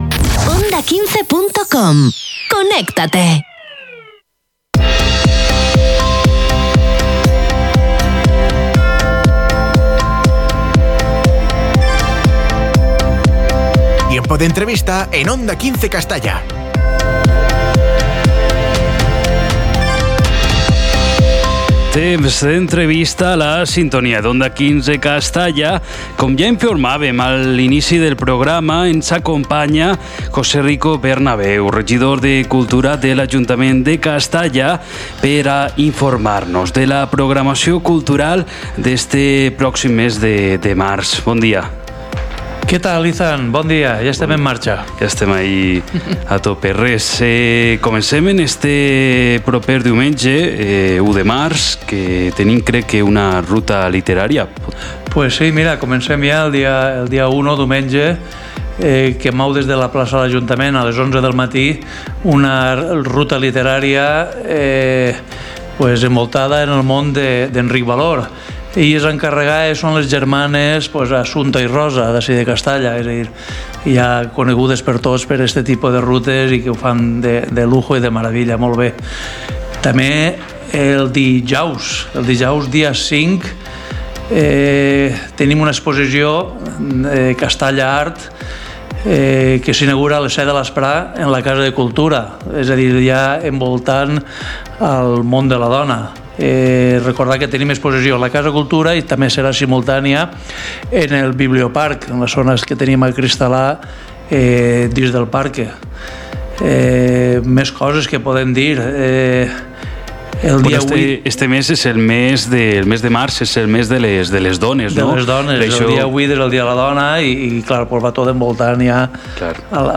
Entrevista a José Rico Bernabeu, regidor de Cultura de l’M.I. Ajuntament de Castalla - Onda 15 Castalla 106.0 FM
Hui, al nostre informatiu, parlem amb José Rico Bernabeu, regidor de Cultura, Comerç i Fires de l’M.I. Ajuntament de Castalla.